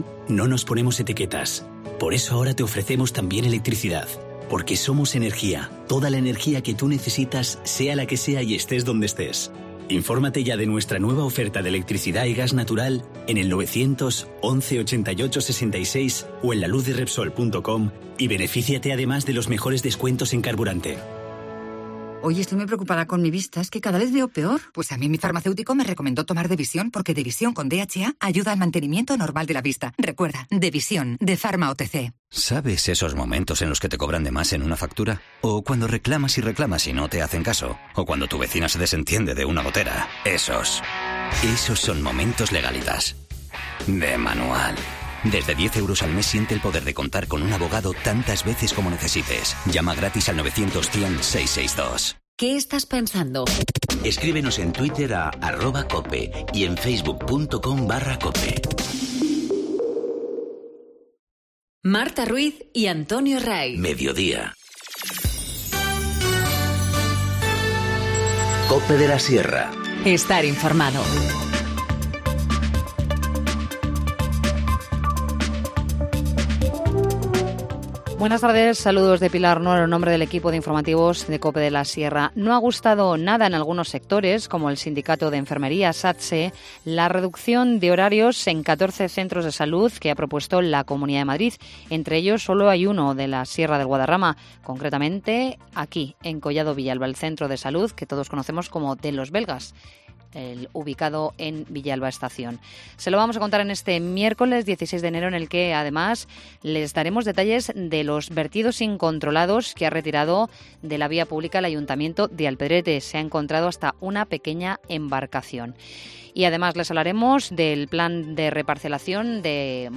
Informativo Mediodía 16 enero- 14:20h